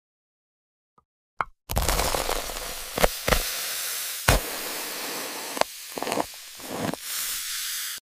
Asmr Frozen Transparent 🍆🔪 Sound Effects Free Download